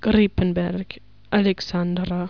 Aussprache Aussprache
gripenbergaleksandra.wav